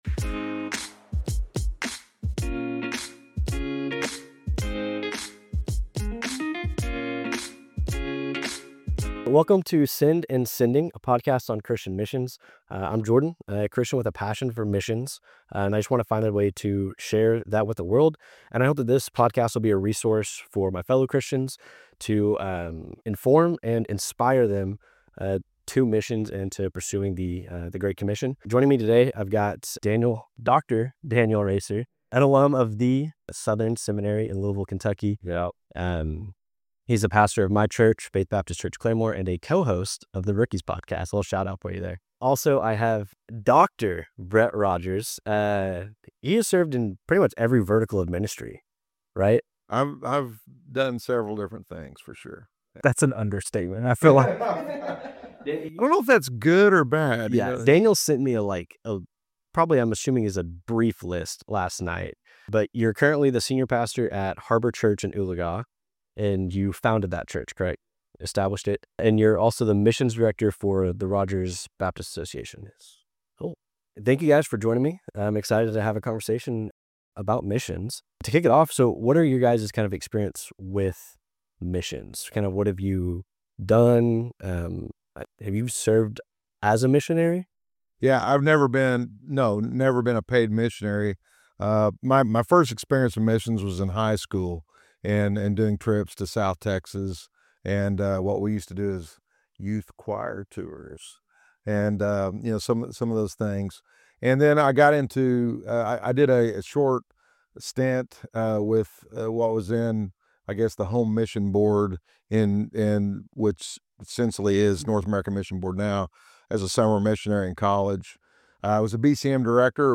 two special guests